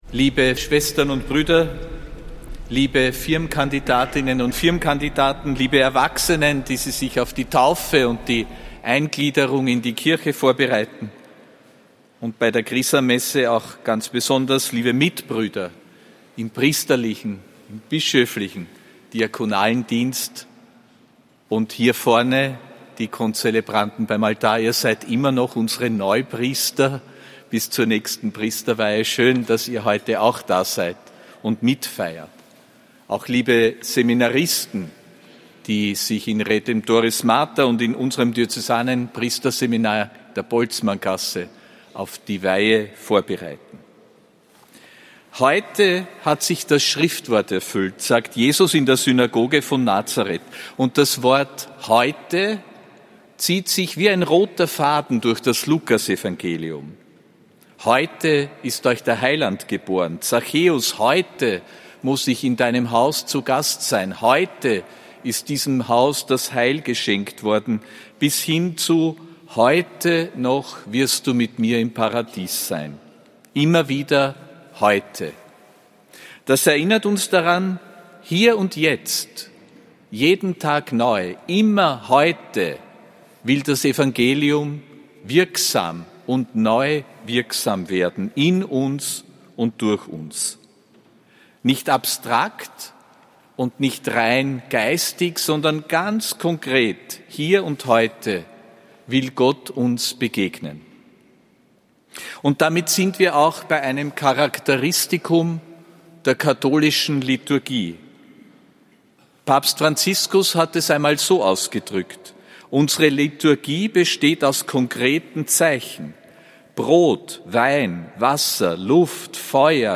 Predigt von Erzbischof Josef Grünwidl bei der Chrisammesse, am 30.